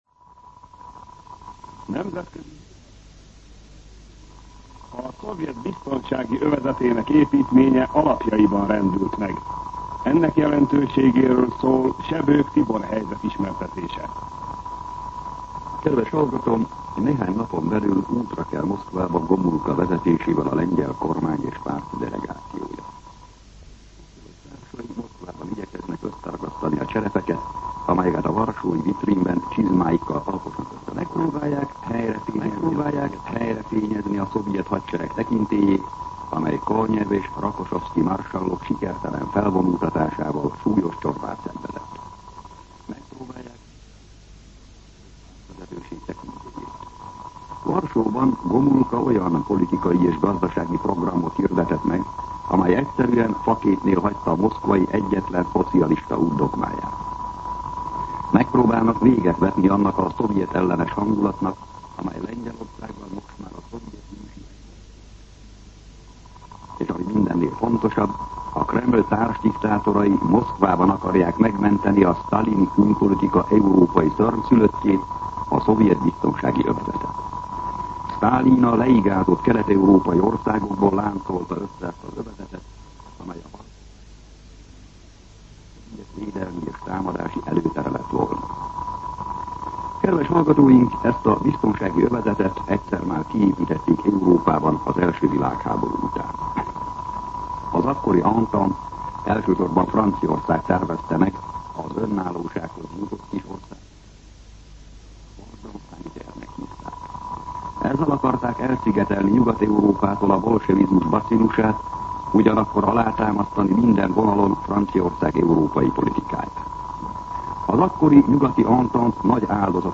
MűsorkategóriaKommentár
Megjegyzés[…] adáshiba